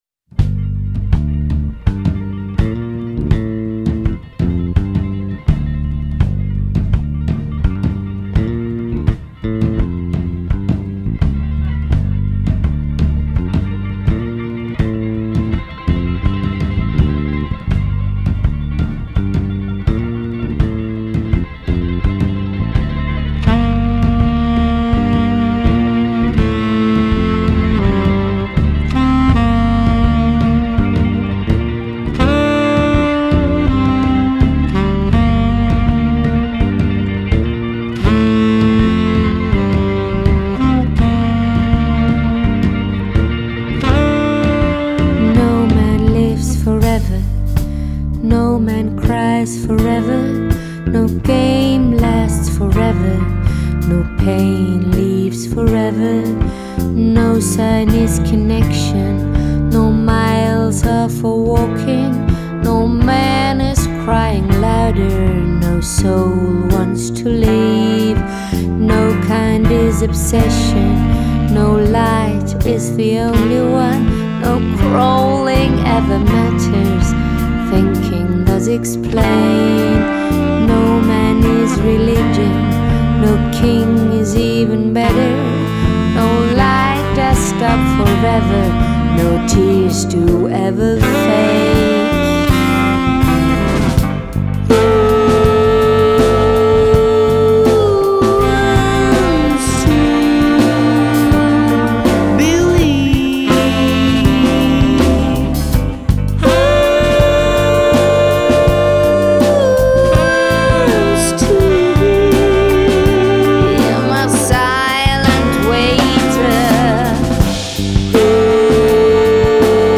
vocals
bass
saxes
guitar / electric sitar
drums